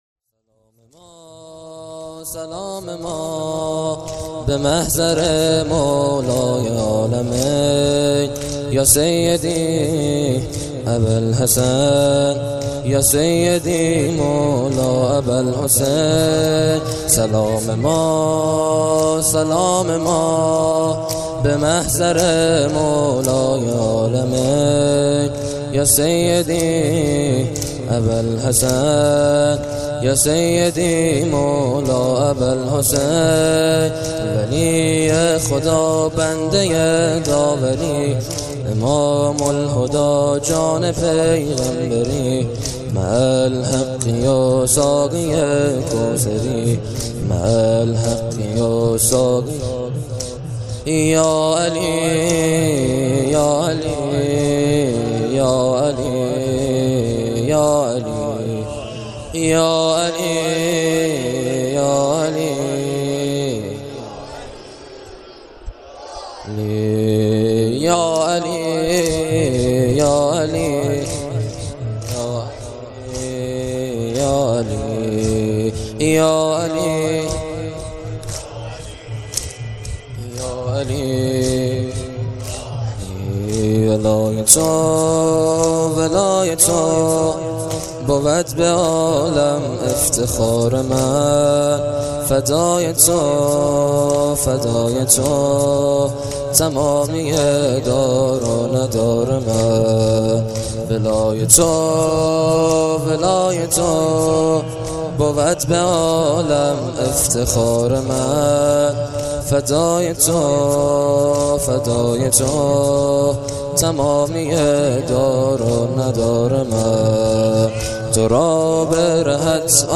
خیمه گاه - هیأت الجواد (رهروان امام و شهدا) - زمینه سلام ما به محضر مولای عالمین